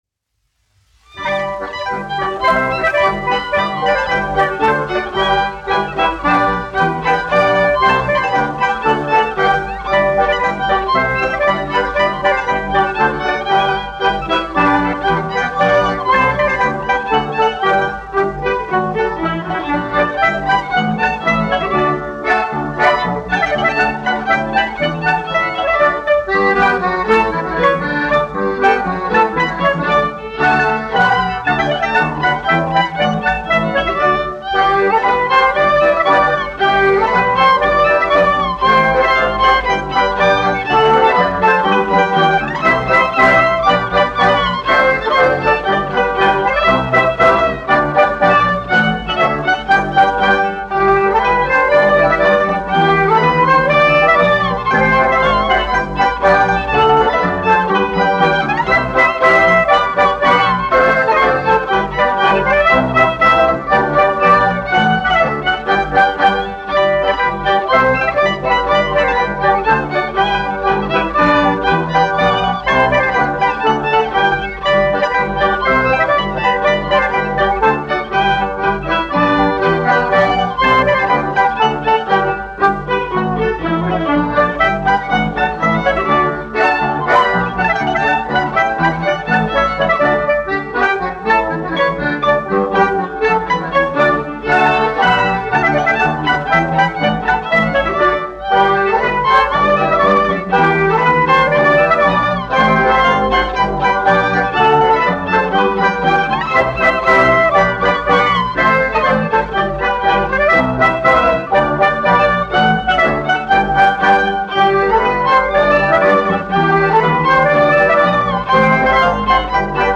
Jautraviņa : tautas deja
1 skpl. : analogs, 78 apgr/min, mono ; 25 cm
Latviešu tautas dejas
Latvijas vēsturiskie šellaka skaņuplašu ieraksti (Kolekcija)